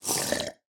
Minecraft Version Minecraft Version snapshot Latest Release | Latest Snapshot snapshot / assets / minecraft / sounds / mob / wandering_trader / drink_milk5.ogg Compare With Compare With Latest Release | Latest Snapshot
drink_milk5.ogg